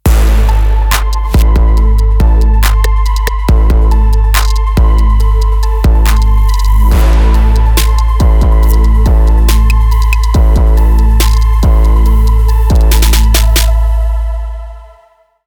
Authentic 808 grooves in Clean, Chromed Tape, Dirty Tape & Xtra modes. Bonus synth/piano tones & Live rack included.
These sounds are directly sourced from the original machines, ensuring every beat carries their unmistakable character and warmth.
808_beatpack_demo.mp3